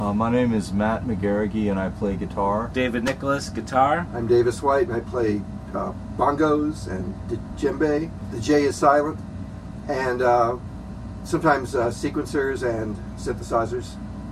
sansyou-answer1.mp3